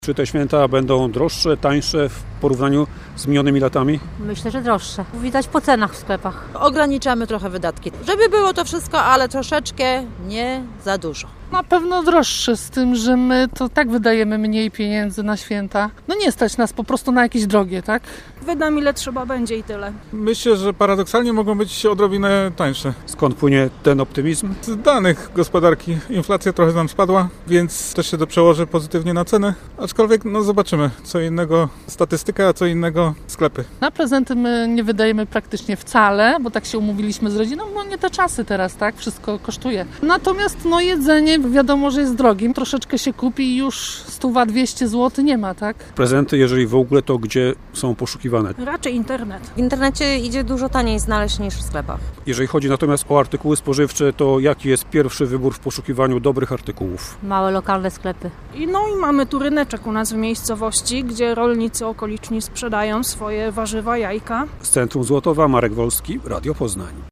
Między innymi o tym z mieszkańcami Złotowa rozmawiał nasz reporter.
- mówili mieszkańcy.